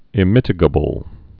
(ĭ-mĭtĭ-gə-bəl)